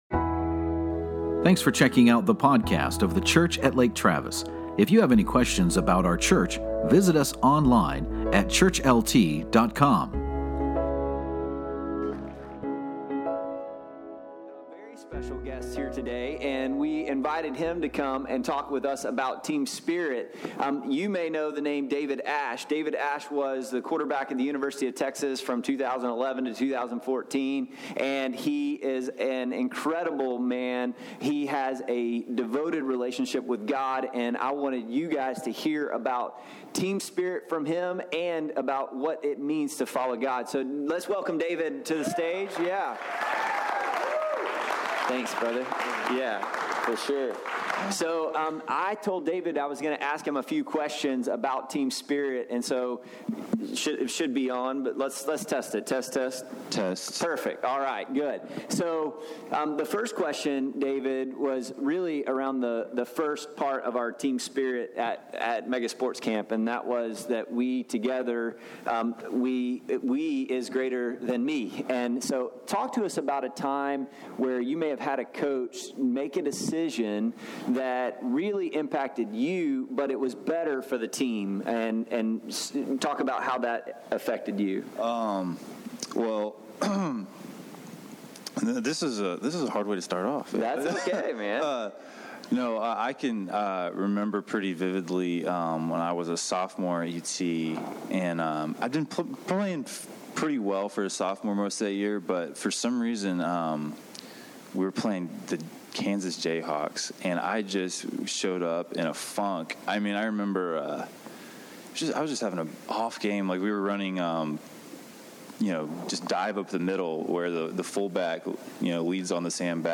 You can catch that interview in the first 17 minutes and 30 seconds. In today’s message, we asked the question what if God were like ice cream, peanut butter […]